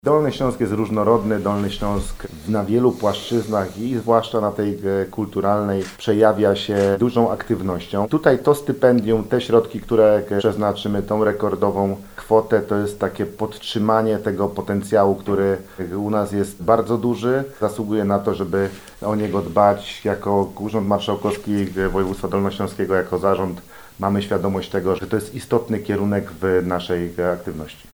Realizowane projekty promują zdolnych artystów oraz cały region, dlatego warto ich wspierać – mówi Marszałek Województwa Dolnośląskiego Paweł Gancarz.